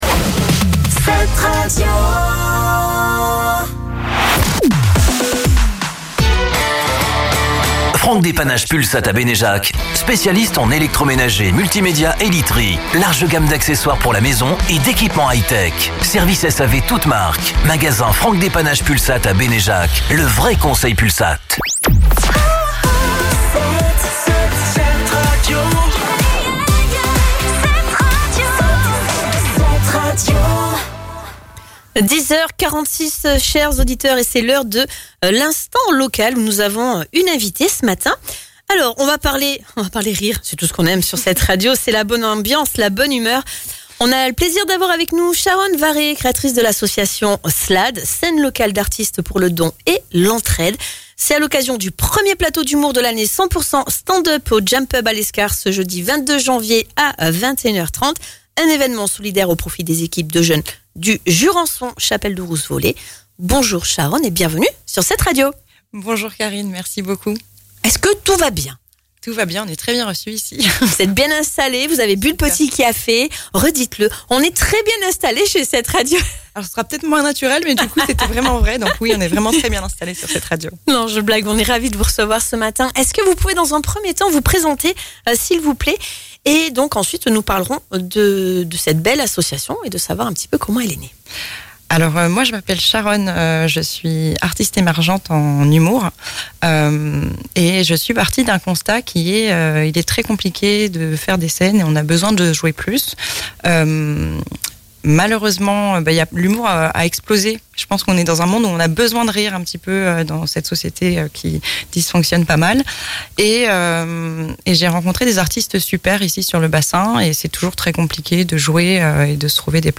Un échange inspirant autour du tout premier JAM COMEDY SHOW de l'année , de la solidarité et de l’engagement local.